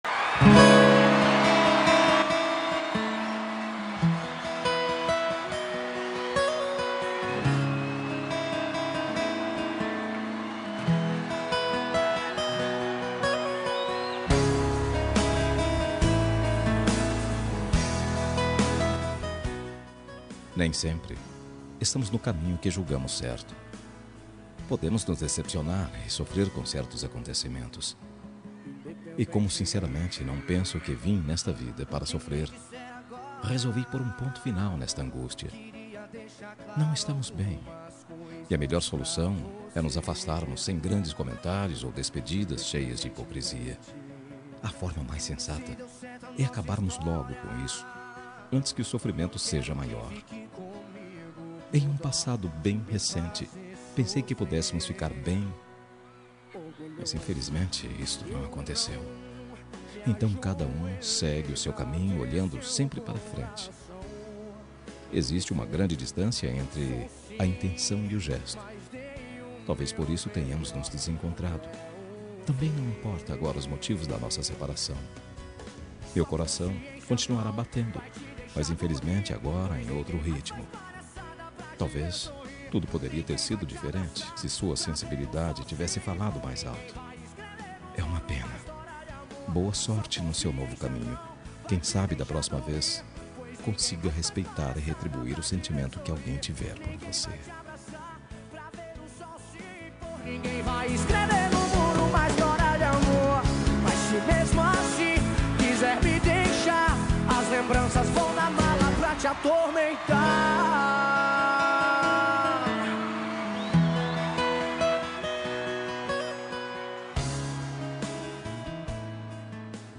Término – Voz Masculina – Código: 8667